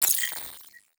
Robotic Game Notification 6.wav